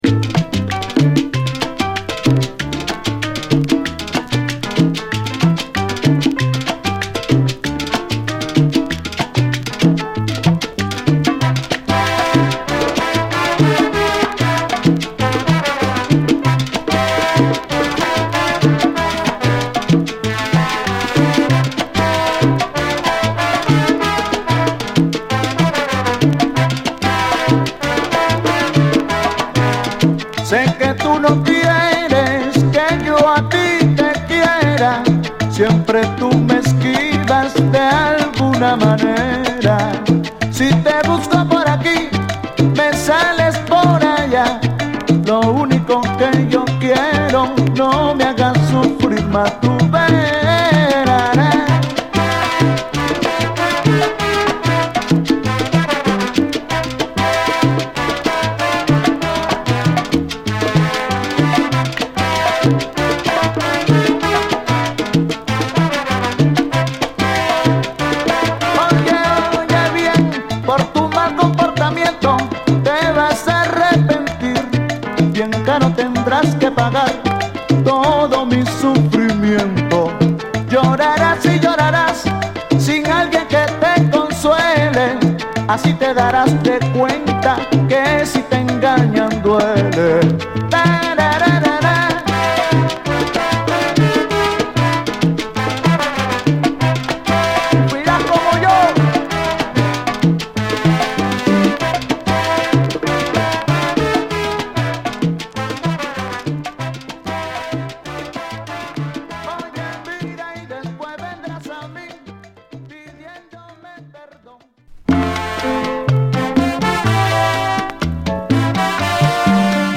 哀愁のフレーズを力強く奏でるホーン隊が印象的な、ピリっと引き締まったラテン・ダンサー！